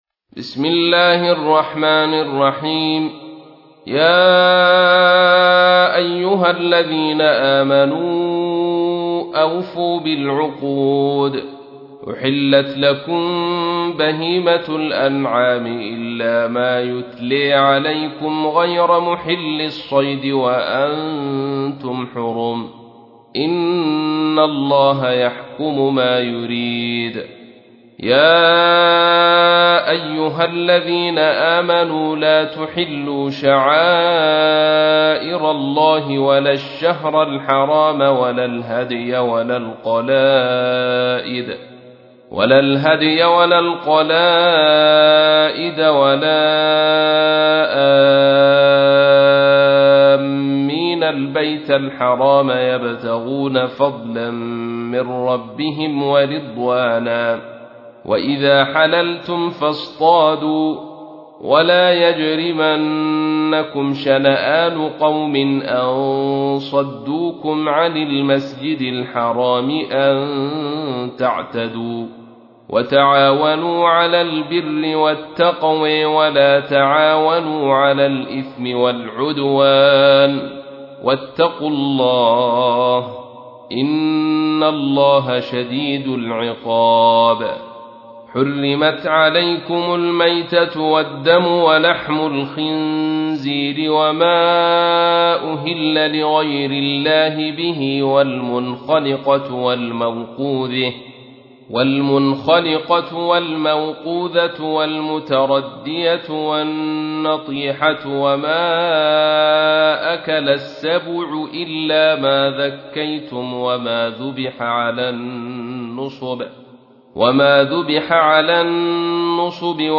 تحميل : 5. سورة المائدة / القارئ عبد الرشيد صوفي / القرآن الكريم / موقع يا حسين